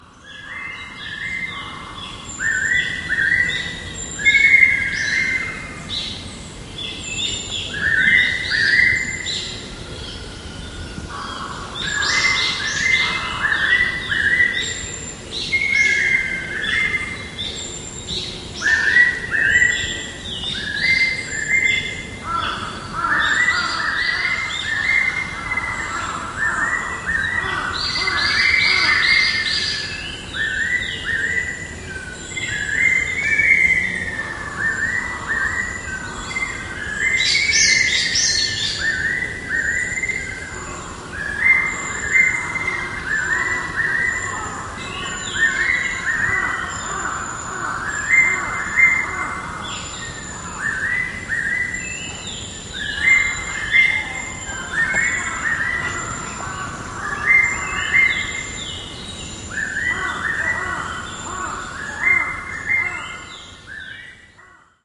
Pitta nympha 八色鳥 別名： 仙八色鶇 學名： Pitta nympha, 夏候鳥
日本 連續重複的粗啞「喔阿、窩阿！」 相似物種： 大彎嘴 S-01 鷹鵑 S-01 辨識要點：大彎嘴一般為兩音為一個曲目，八色鳥則是四音（兩個兩音）為一曲目，且八色鳥音質較粗啞，尾音通常為上揚。